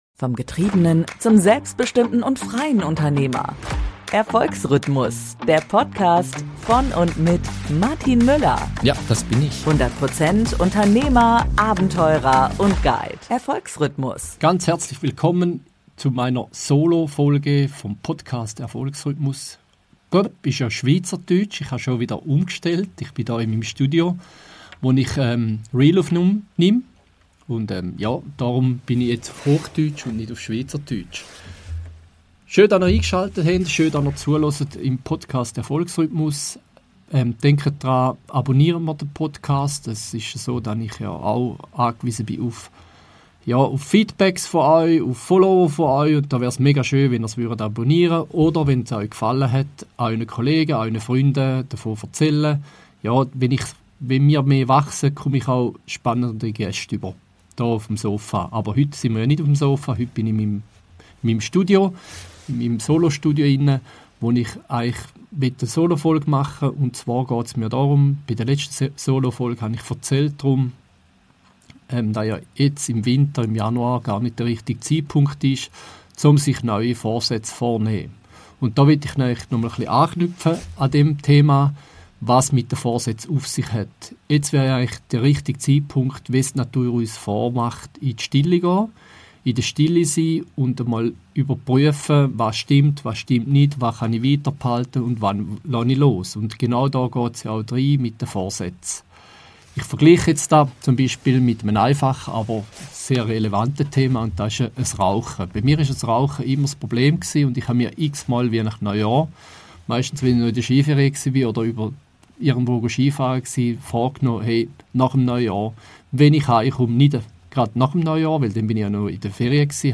In dieser intensiven Solo-Podcast-Folge erkläre ich, warum Gewohnheiten der härteste Klebstoff der Welt sind – und warum Verbote und Willenskraft niemals funktionieren.